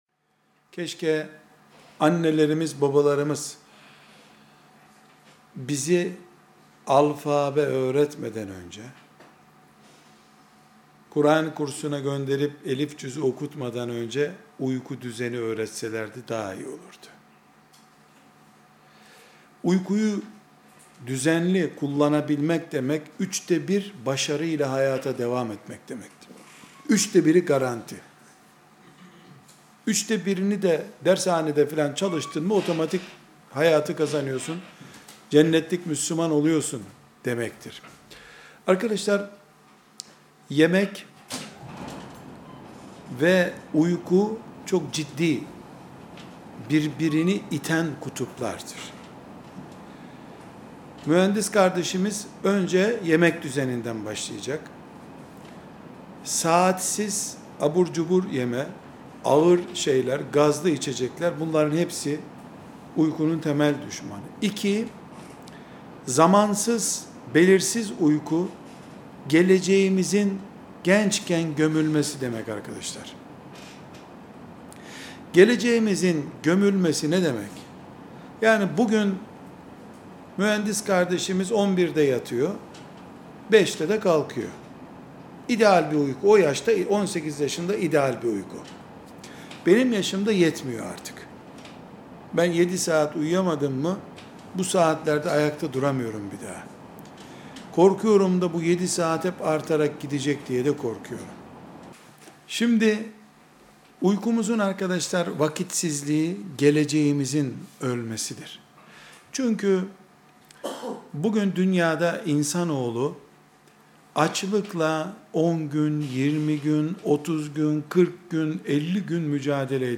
2. Soru & Cevap